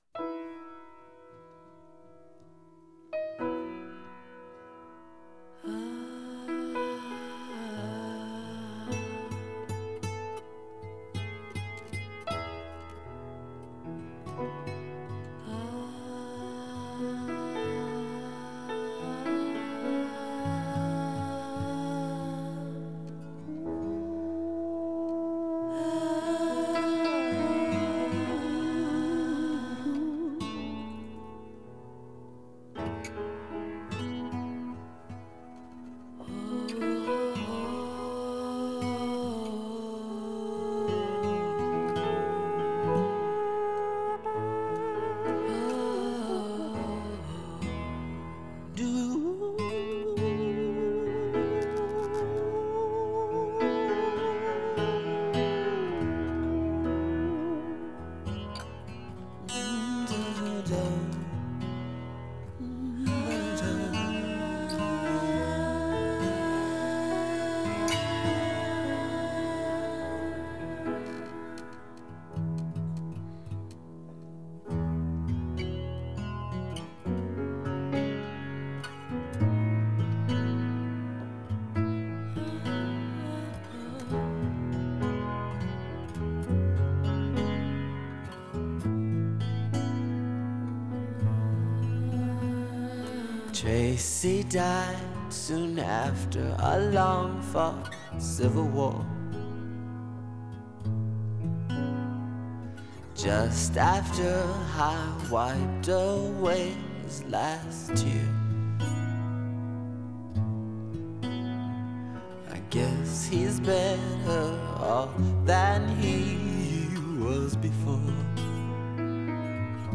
It sounds almost played and recorded on-the-hoof.